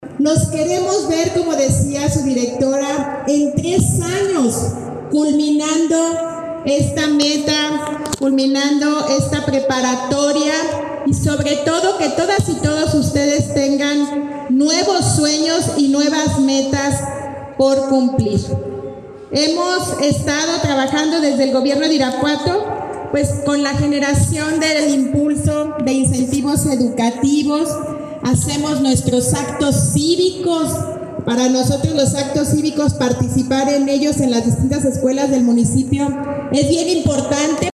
Lorena Alfaro García, presidenta municipal